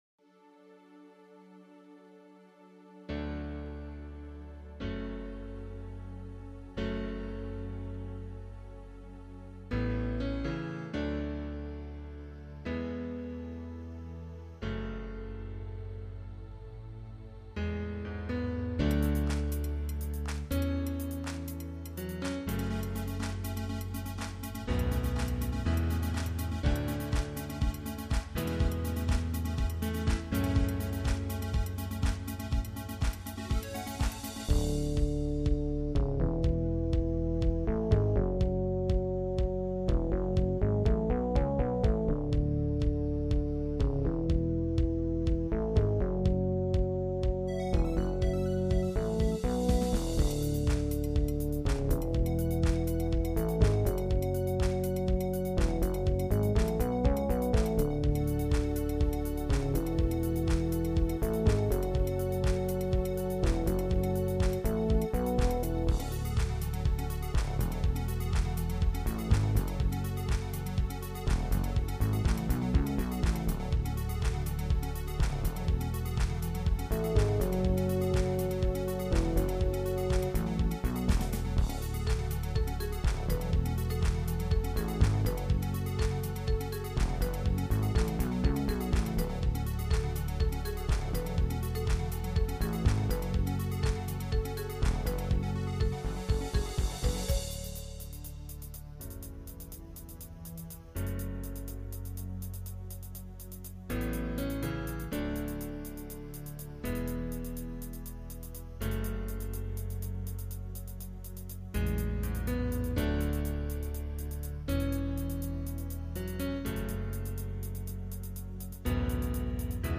PRO MIDI INSTRUMENTAL VERSION